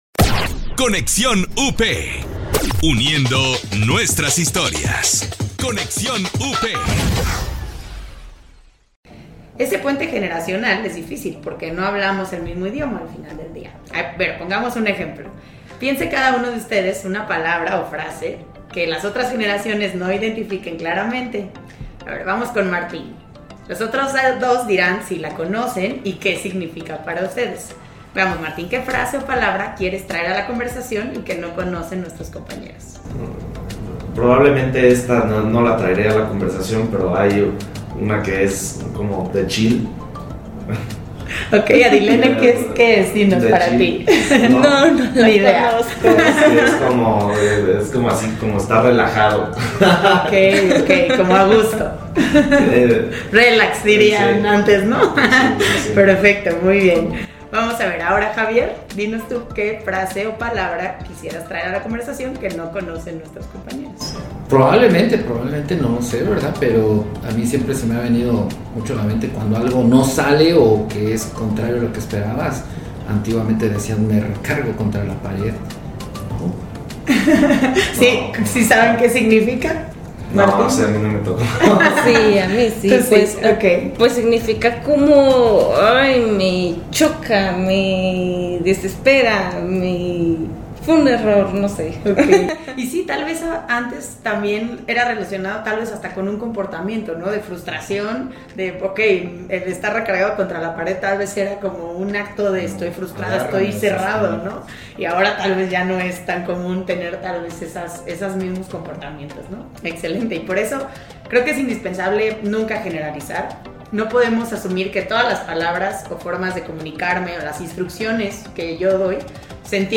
En este episodio reunimos a tres invitados representando a varias generaciones que pertenecen a la Gran Familia Pilgrim's.